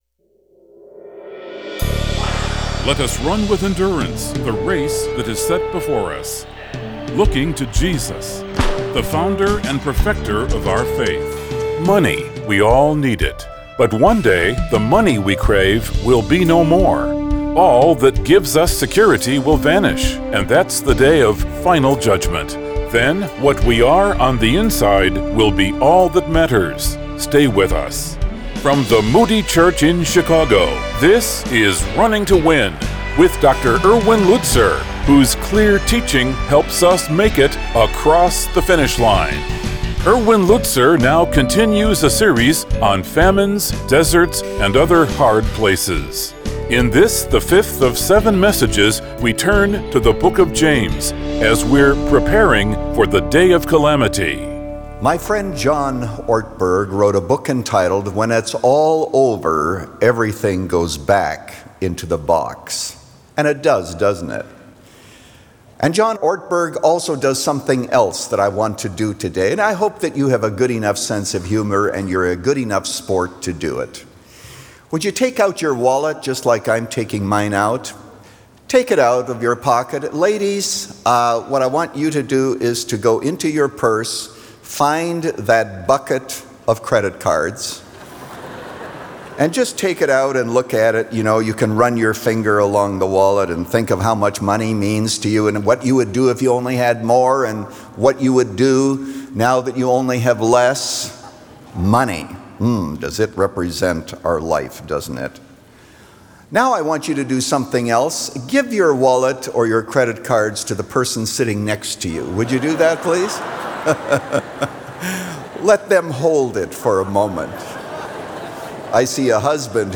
Preparing For The Day Of Calamity – Part 1 of 3 | Radio Programs | Running to Win - 15 Minutes | Moody Church Media